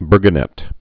(bûrgə-nĭt, bûrgə-nĕt)